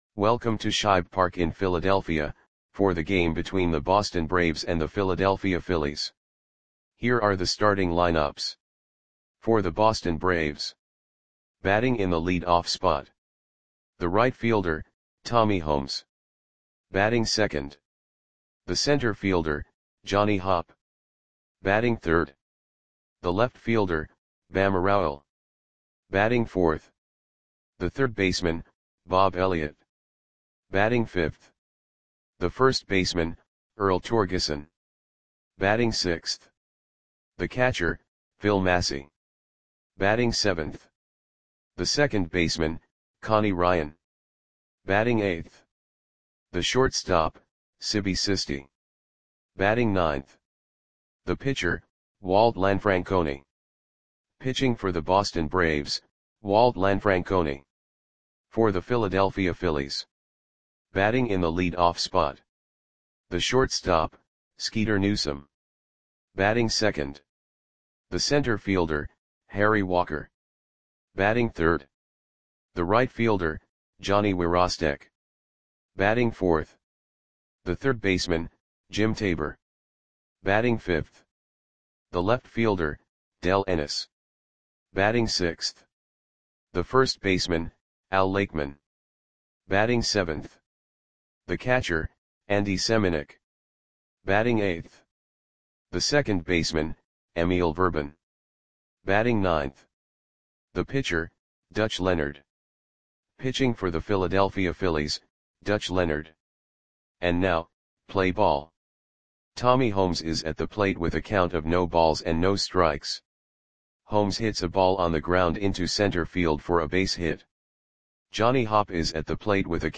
Audio Play-by-Play for Philadelphia Phillies on July 4, 1947
Click the button below to listen to the audio play-by-play.